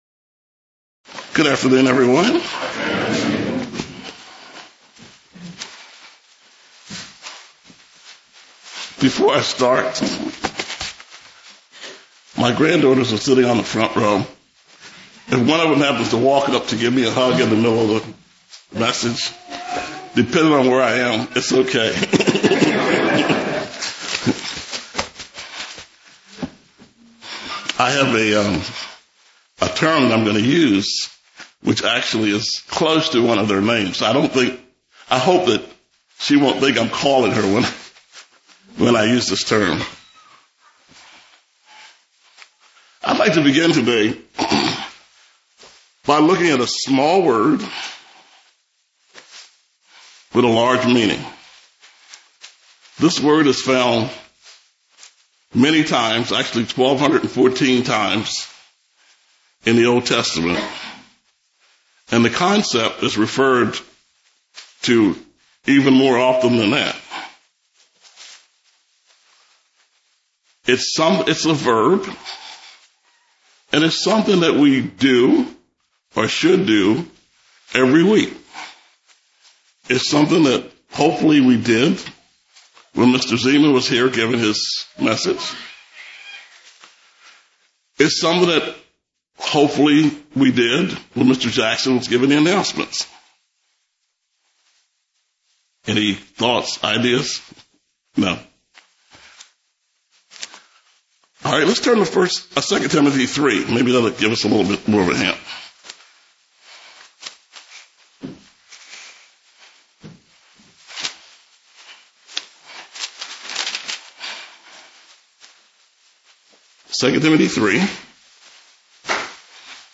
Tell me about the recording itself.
Given in Columbia, MD